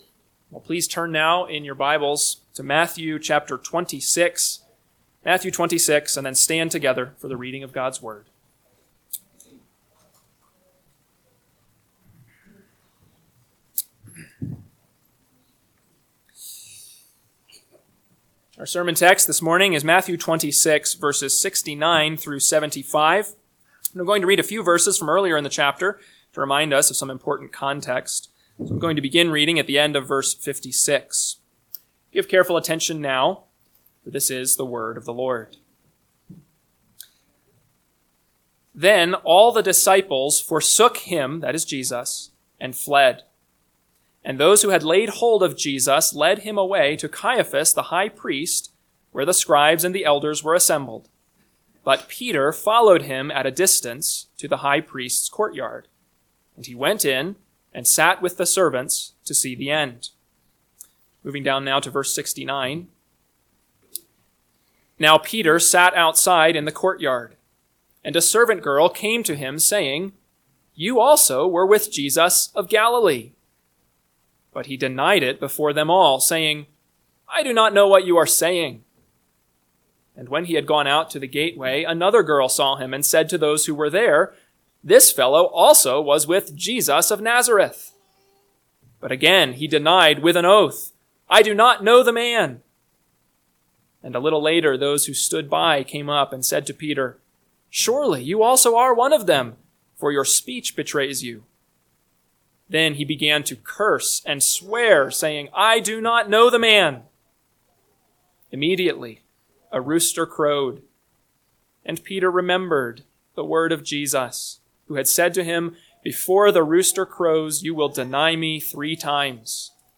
AM Sermon – 3/30/2025 – Matthew 26:69-75 – Northwoods Sermons